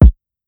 KICK 2.wav